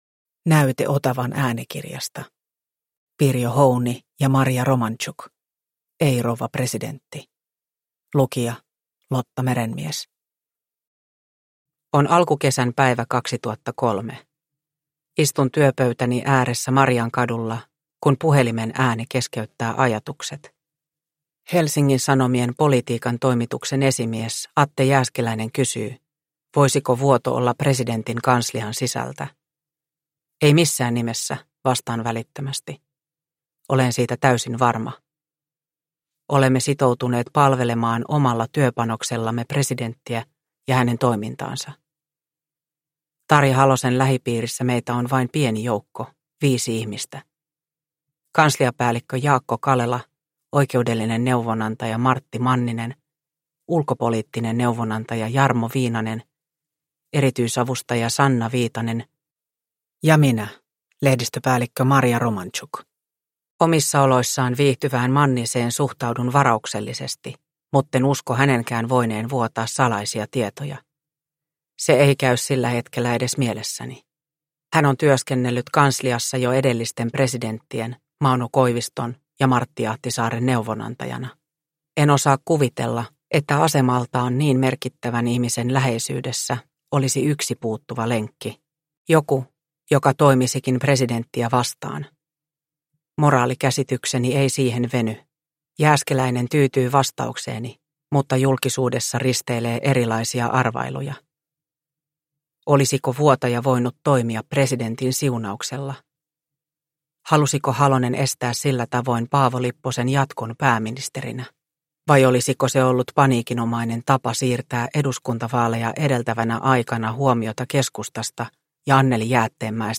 Ei, rouva presidentti – Ljudbok – Laddas ner